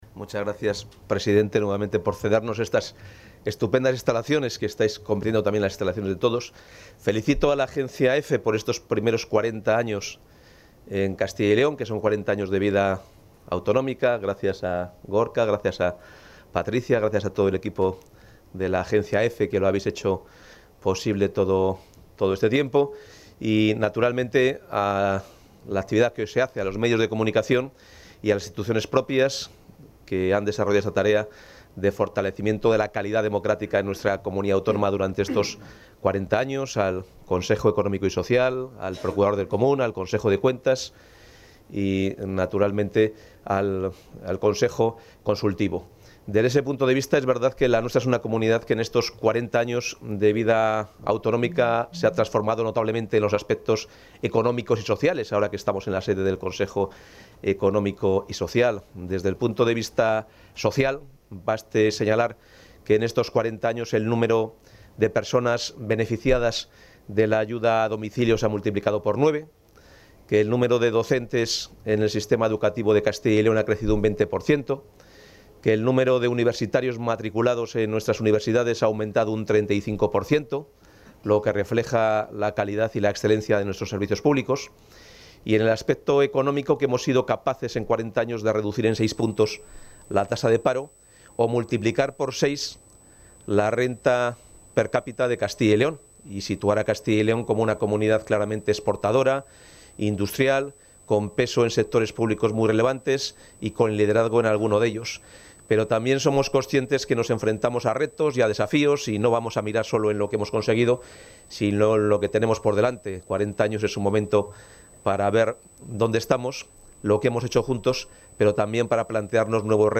Intervención del consejero.
El consejero de Economía y Hacienda, Carlos Fernández Carriedo, ha participado hoy en la jornada EFE Fórum 40 años de EFE en Castilla y León: 'Las instituciones propias y los medios en el desarrollo del Estatuto de Autonomía de Castilla y León'.